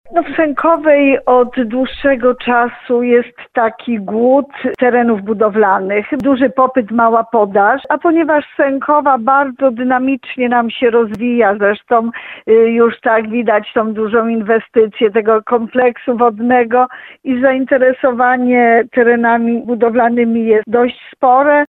Jak tłumaczy wójt Małgorzata Małuch, chodzi o ułatwienie osiedlania się na ternie gminy nowych mieszkańców.